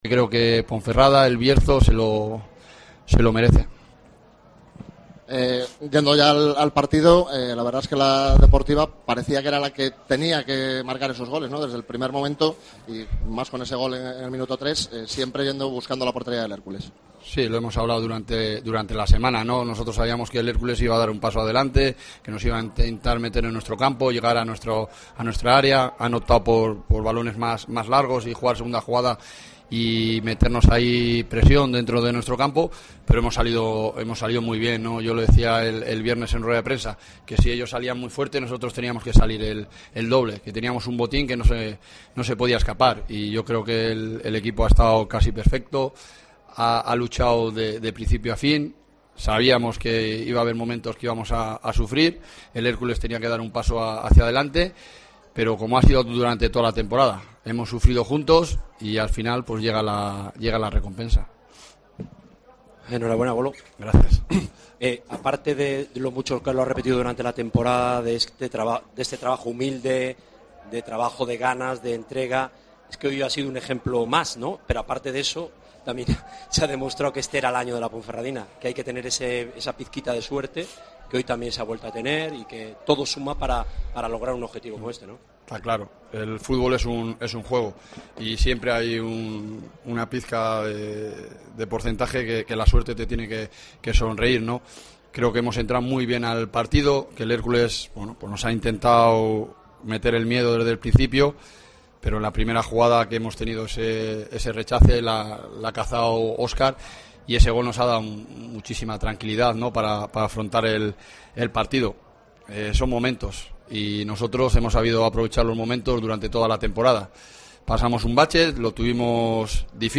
Declaraciones del míster de la Deportiva Ponferradina, Jon Pérez Bolo, tras el ascenso a Segunda A